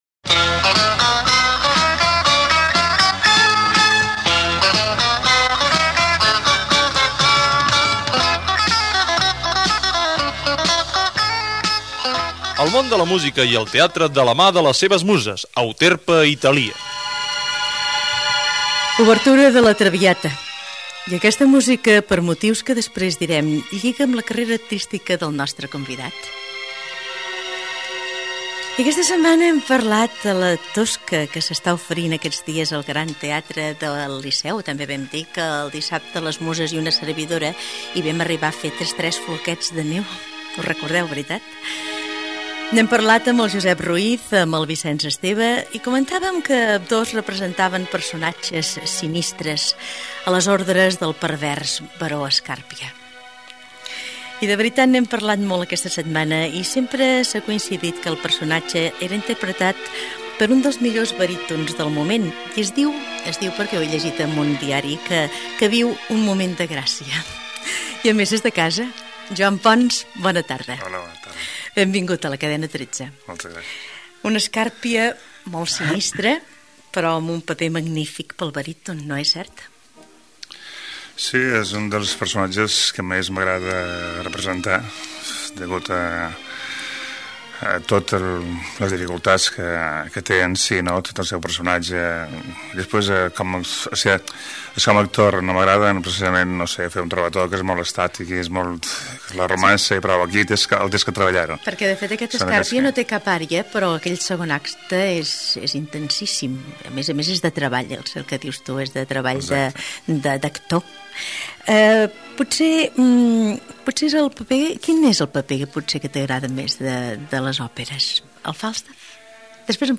Euterpe i Talia: entrevista Joan Pons - Cadena 13, 1985